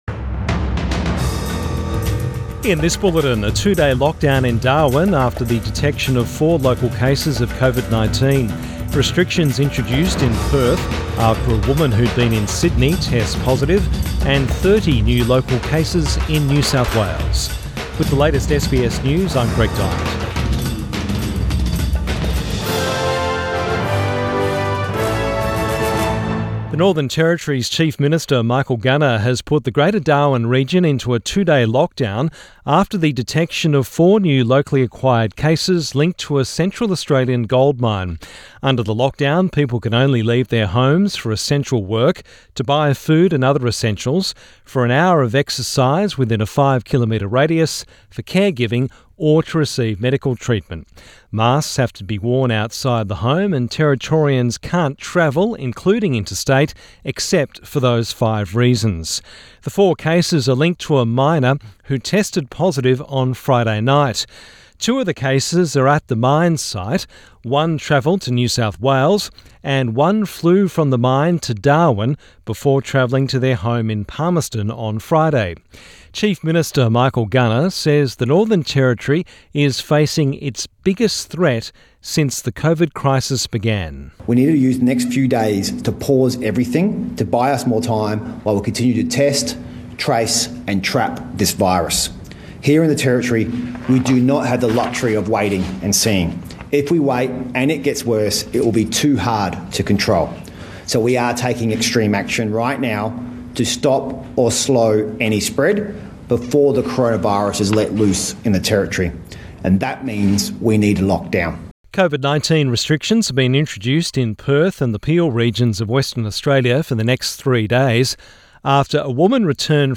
PM bulletin 27 June 2021